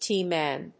/tíː‐(米国英語)/